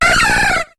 Cri de Floravol dans Pokémon HOME.